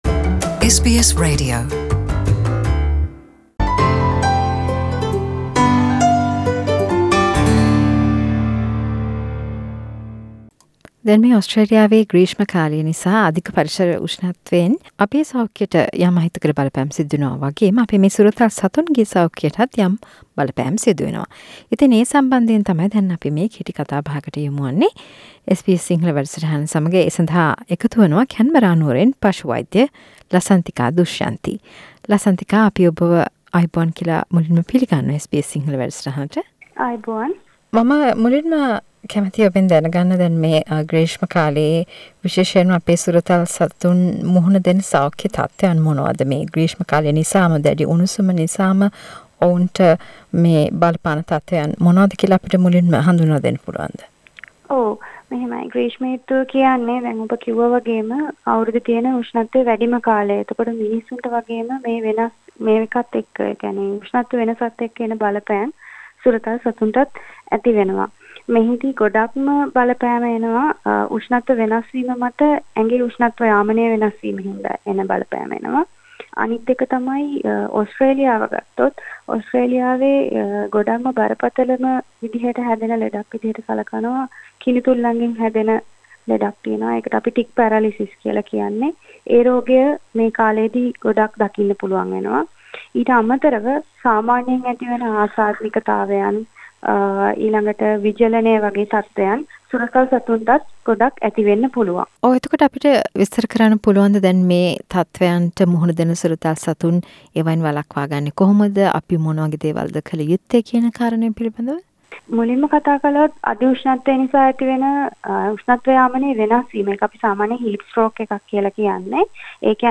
සාකච්චාවක්